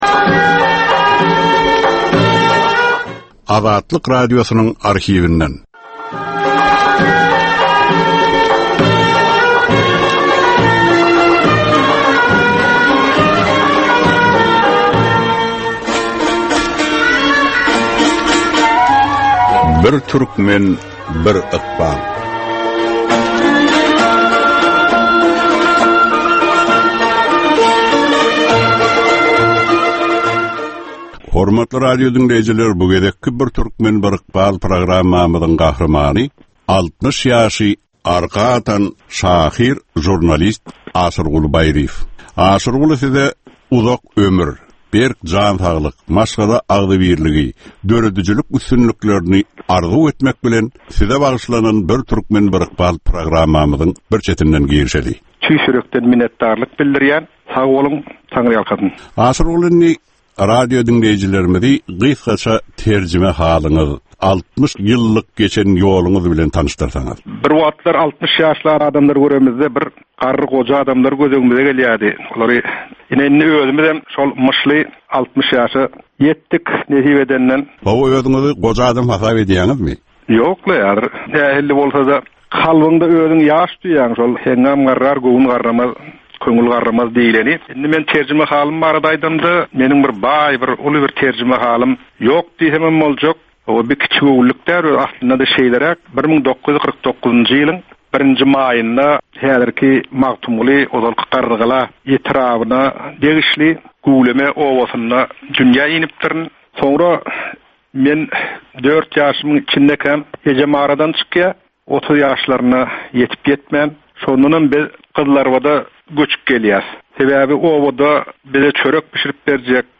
Türkmenistan we türkmen halky bilen ykbaly baglanyşykly görnükli şahsyýetlerin ömri we işi barada ýörite gepleşik. Bu gepleşikde gürrüňi edilýän gahrymanyň ömri we işi barada ginişleýin arhiw materiallary, dürli kärdäki adamlaryň, synçylaryň, bilermenleriň, žurnalistleriň we ýazyjy-şahyrlaryň pikirleri, ýatlamalary we maglumatlary berilýär.